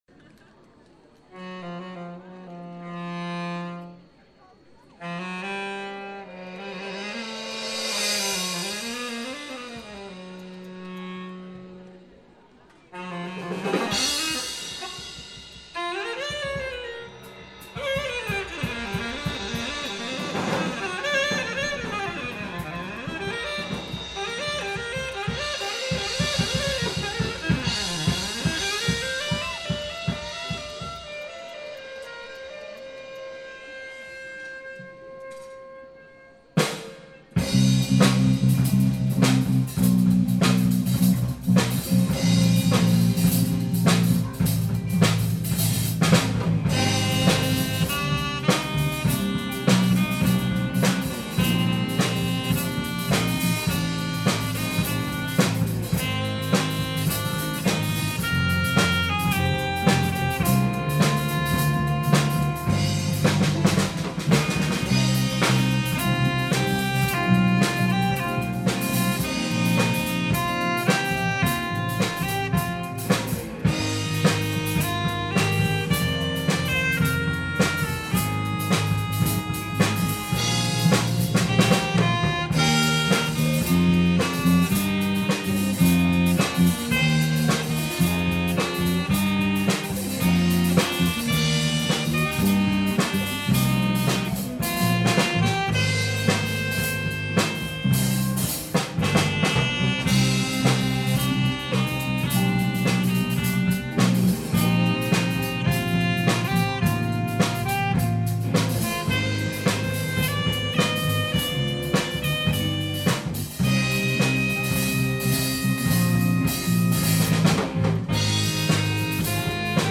* Cliquer sur les photos pour les voir plus en détail * "Le P'tit Groupe" , ATELIER 2013 de bRAINSdEmUSIQUE bande son bande son bande son bande son * retour aux auditions & concerts passés, en images...